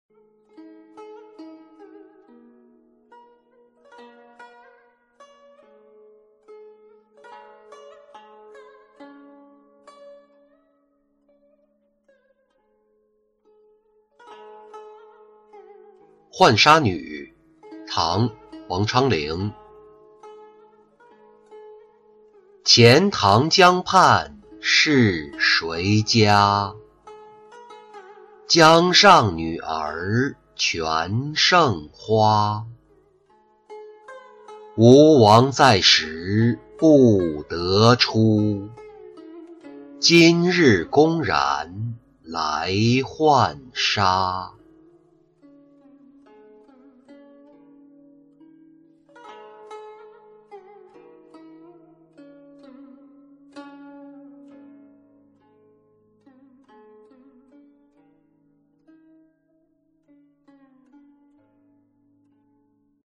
浣纱女-音频朗读